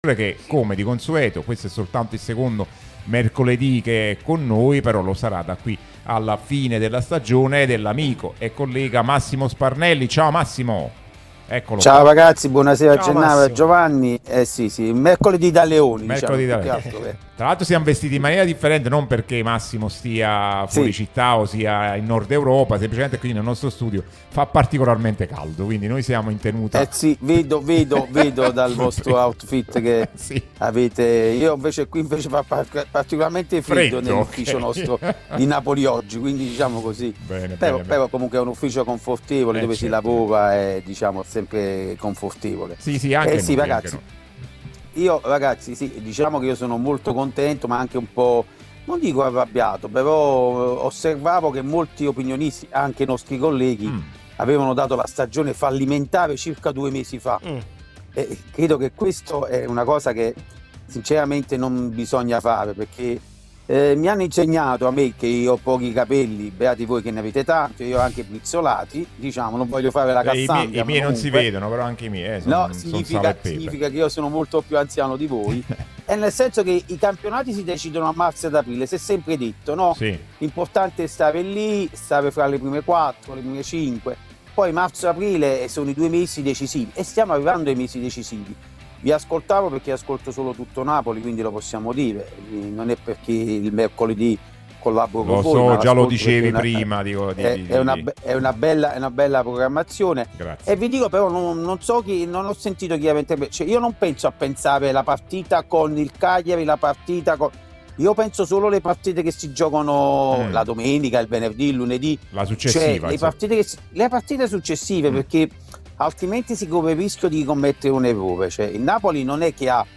l'unica radio tutta azzurra e sempre live